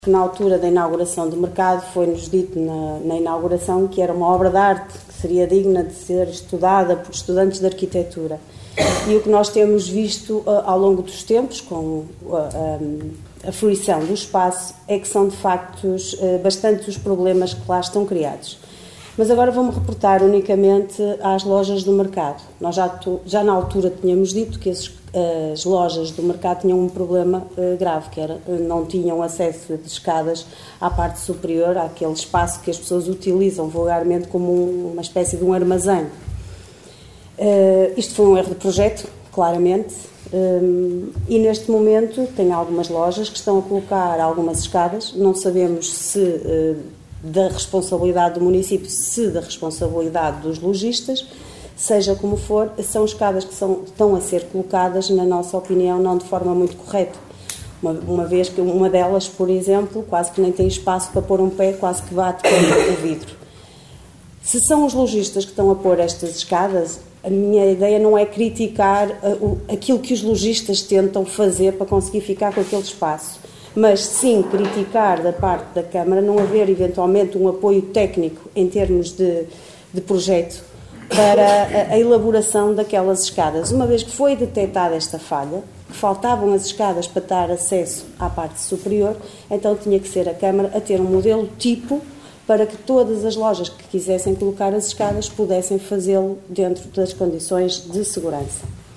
A afirmação foi feita pela vereadora da Coligação O Concelho em Primeiro (OCP), Liliana Silva, na última reunião de Câmara. A vereadora referia-se muito concretamente à falta de escadas de acesso aos armazéns das lojas do mercado, escadas essas que estão agora a ser feitas pelos arrendatários quando na opinião da Coligação OCP deveriam ter sido feitas aquando da empreitada de construção daquele equipamento.